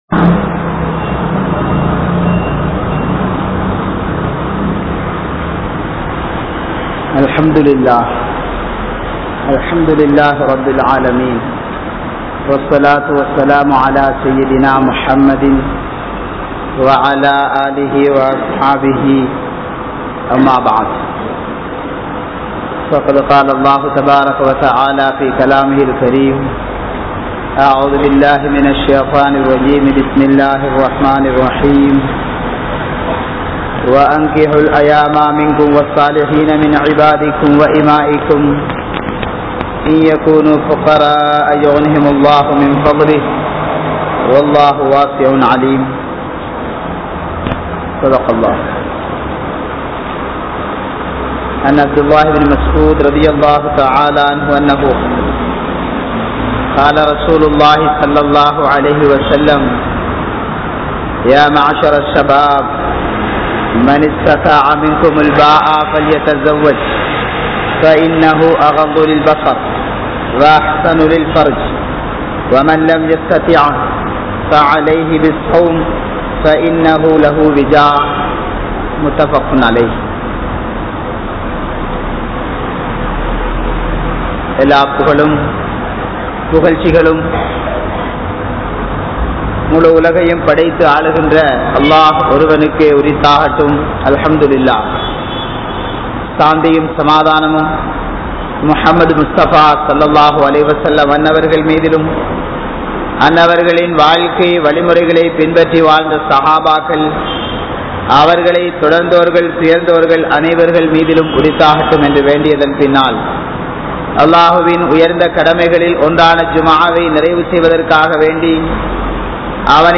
NIkkah Ean Avasiyam? (நிக்காஹ் ஏன் அவசியம்?) | Audio Bayans | All Ceylon Muslim Youth Community | Addalaichenai